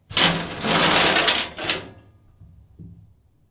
联系麦克风向玩具车倾倒石块
描述：当我把鹅卵石放入卡车的床上时，用金属玩具卡车上的麦克风接触
标签： 卵石 压电 命中 摇滚 唠叨 拍打 岩石 金属 拨浪鼓 自来水 卵石
声道立体声